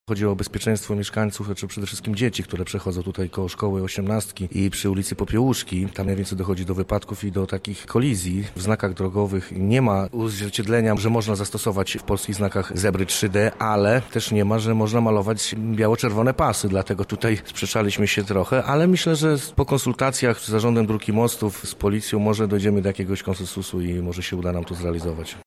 O tym dlaczego radni wyszli z taką propozycją mówi – Tomasz Makarczuk, przewodniczący zarządu dzielnicy Wieniawa.